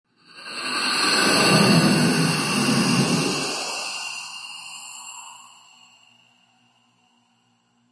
Scary Breath Sound Button - Free Download & Play